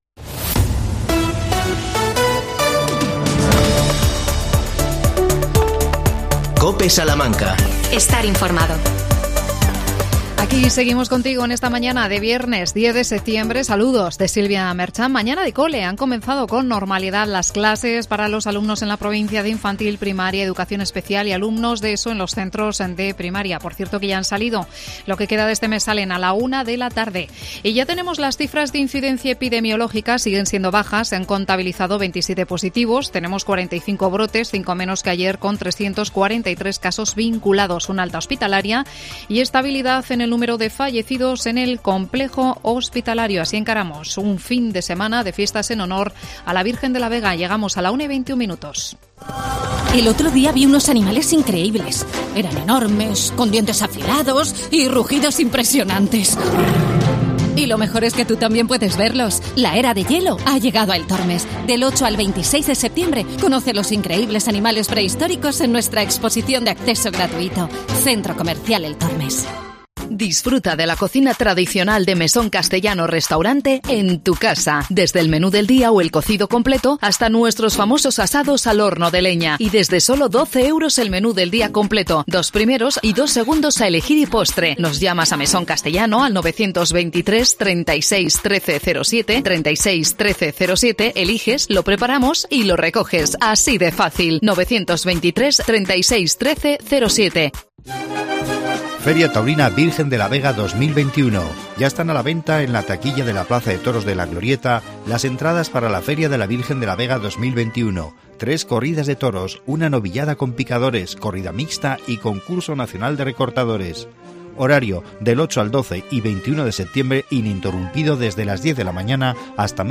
AUDIO: Problemas de telefonía móvil en Monsagro. Hablamos con su alcalde Francisco Mateos.